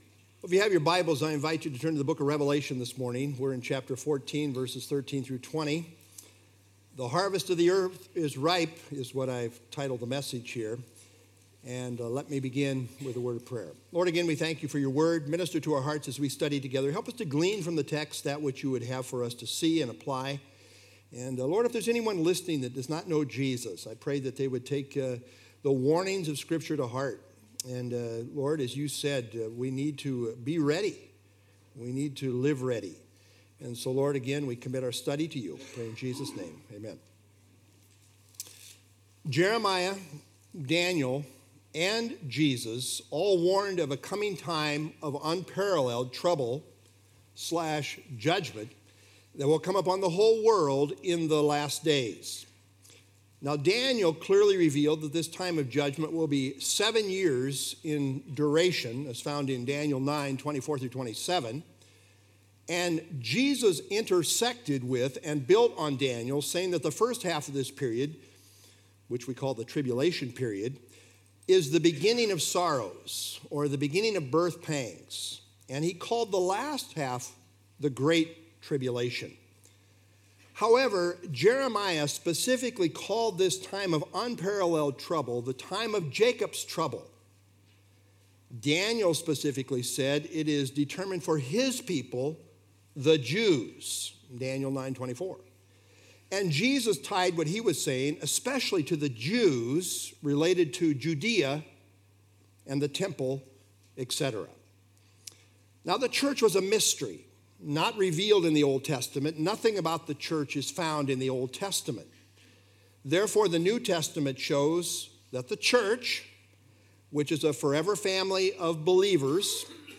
Download FilesRev 14 13-20 Sermon - March 22 2026Revelation 14_13-20